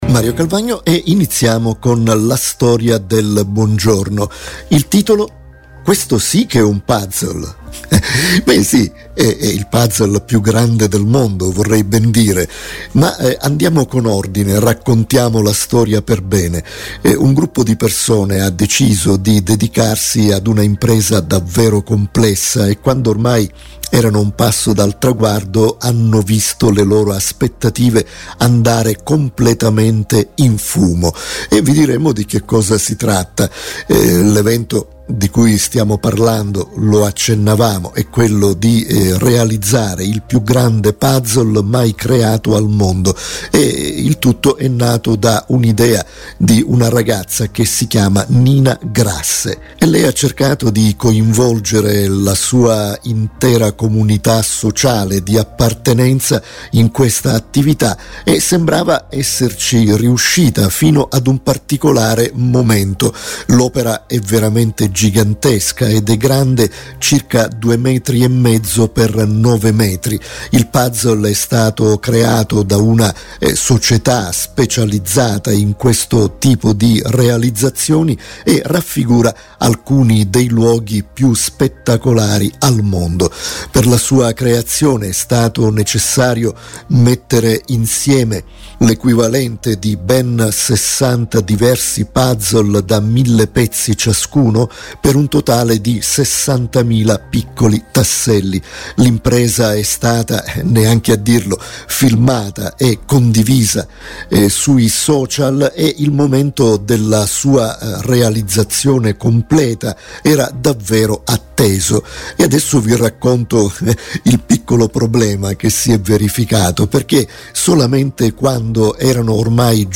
Una storia commentata